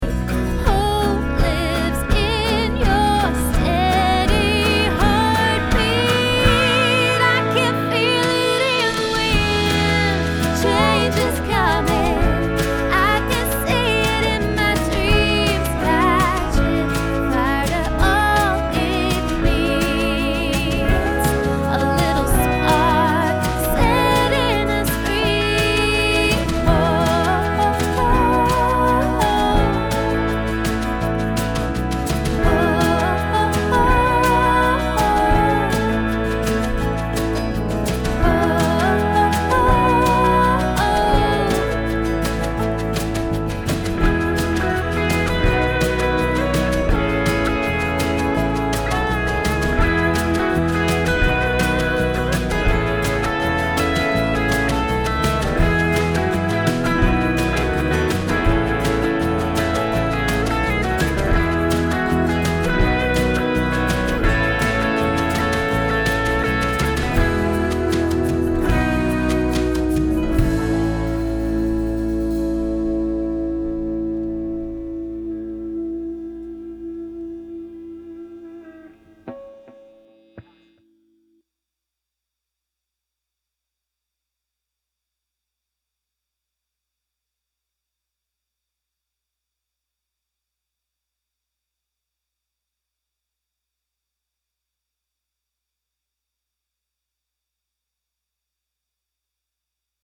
To my finished mix here: